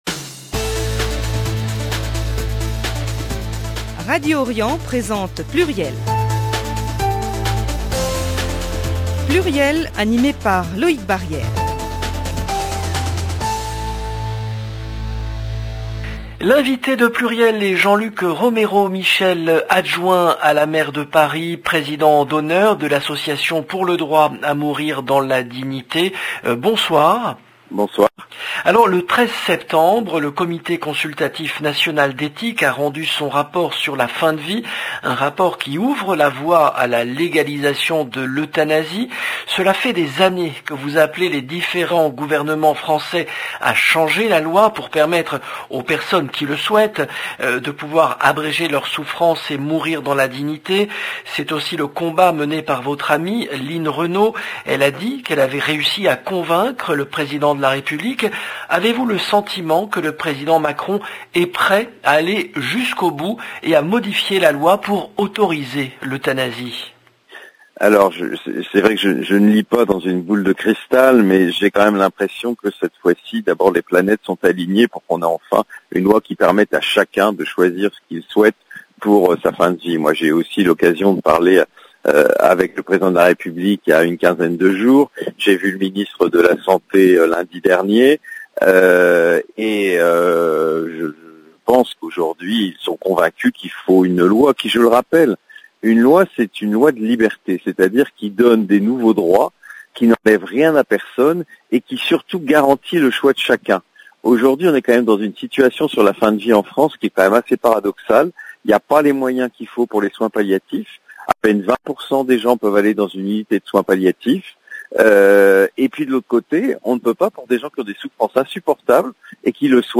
Il répond aux questions sur la fin de vie alors que le 13 septembre, le Comité consultatif national d’éthique a rendu un rapport qui ouvre la voie à la légalisation de l’euthanasie. Emission